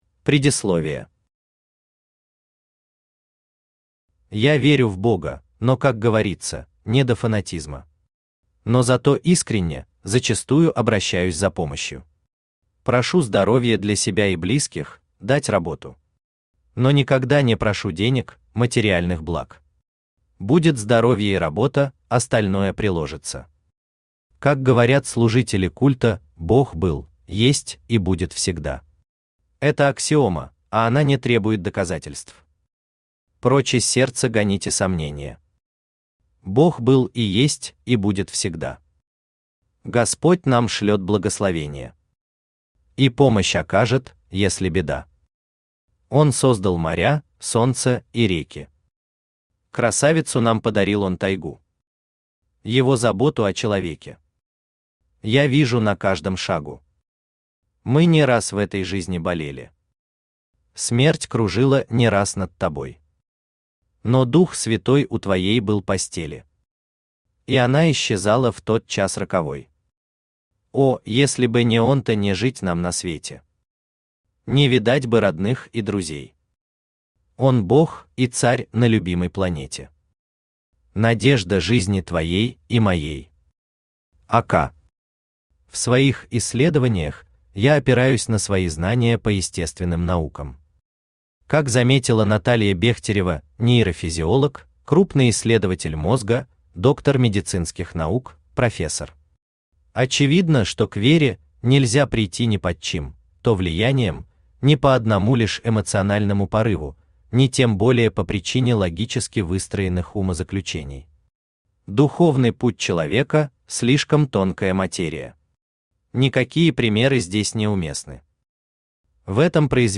Аудиокнига Сознание и вера | Библиотека аудиокниг
Aудиокнига Сознание и вера Автор Виктор Евгеньевич Бабушкин Читает аудиокнигу Авточтец ЛитРес.